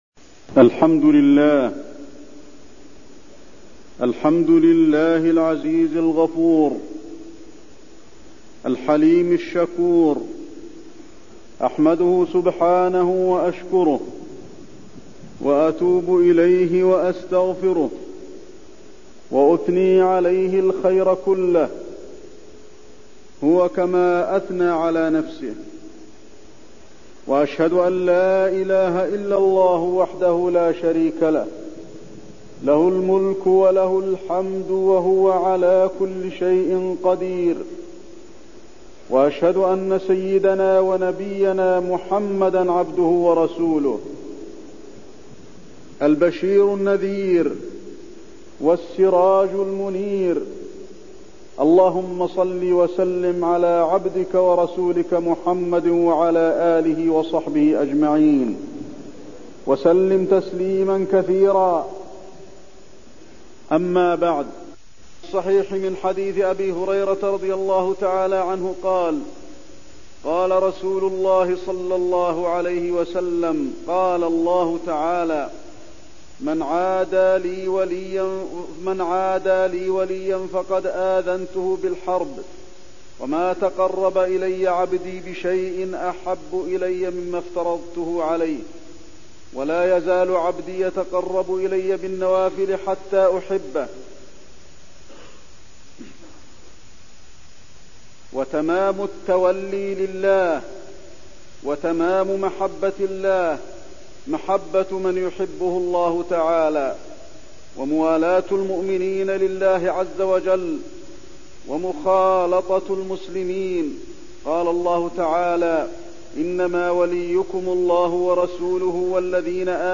تاريخ النشر ٢١ جمادى الأولى ١٤٠٦ هـ المكان: المسجد النبوي الشيخ: فضيلة الشيخ د. علي بن عبدالرحمن الحذيفي فضيلة الشيخ د. علي بن عبدالرحمن الحذيفي الولاية